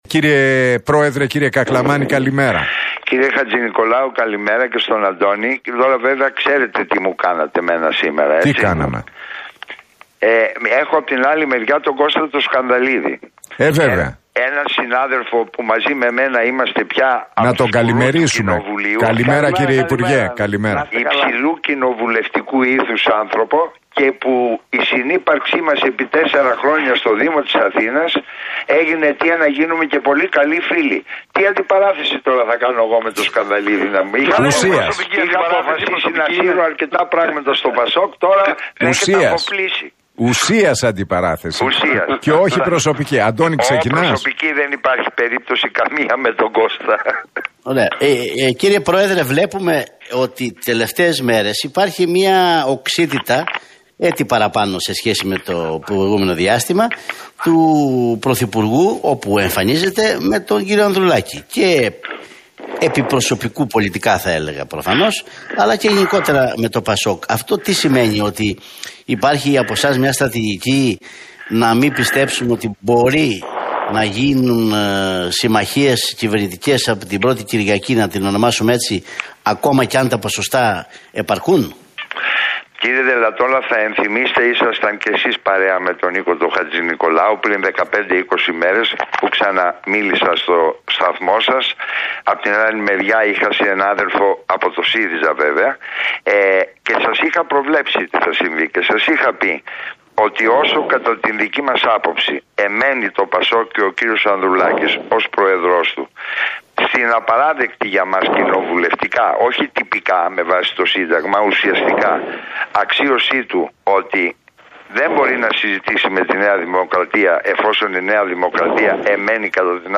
Τα ξίφη τους διασταύρωσαν στον αέρα του Realfm 97,8 και την εκπομπή του Νίκου Χατζηνικολάου, σε ένα debate ο υποψήφιος με τη ΝΔ, Νικήτας Κακλαμάνης και ο υποψήφιος με το ΠΑΣΟΚ – ΚΙΝΑΛ, Κώστας Σκανδαλίδης.